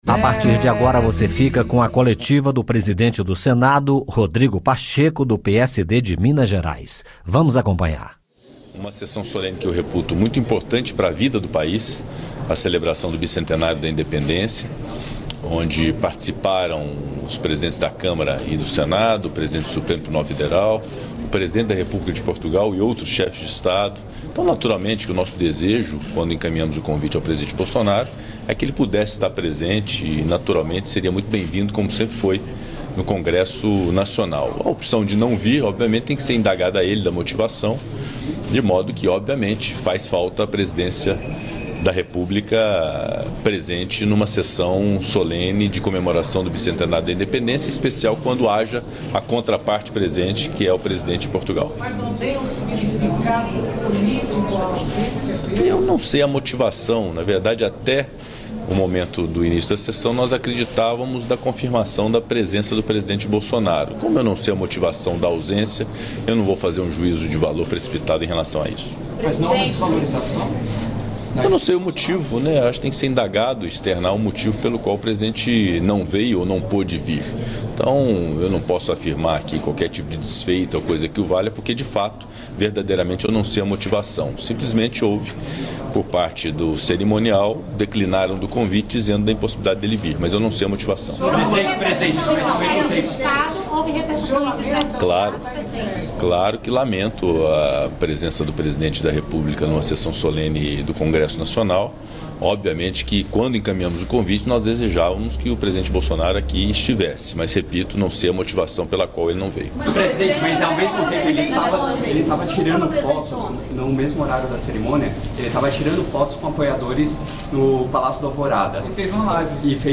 Entrevista coletiva do Presidente do Senado, Rodrigo Pacheco
Ouça a entrevista coletiva do presidente do Congresso Nacional, senador Rodrigo Pacheco, sobre a sessão solene realizada em comemoração ao Bicentenário da Independência.